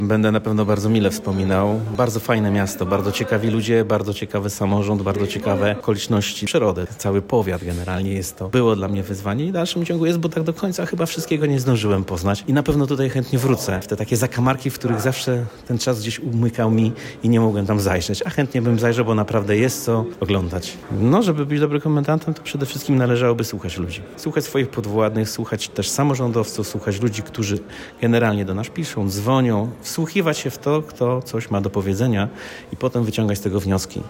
Na oficjalnych uroczystościach w Stargardzkim Centrum Kultury zdał stanowisko komendanta na ręce komendanta wojewódzkiego Tomasza Trawińskiego.
Mówi inspektor Robert Nowak.